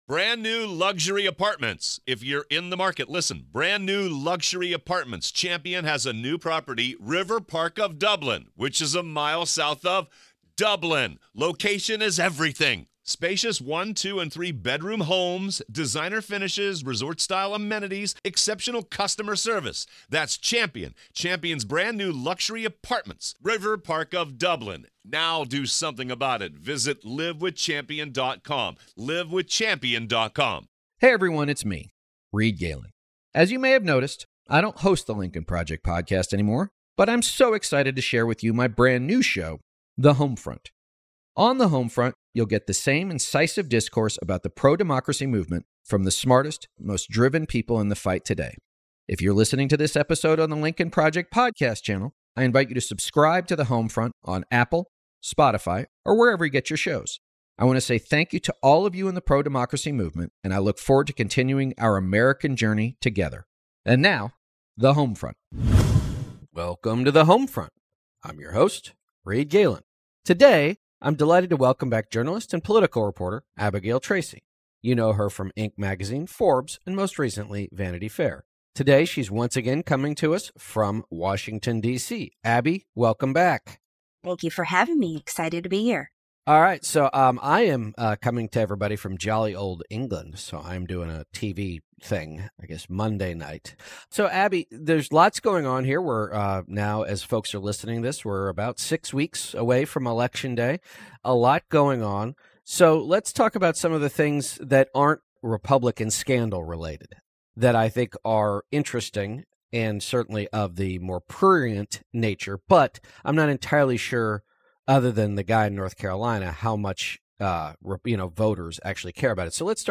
journalist and political reporter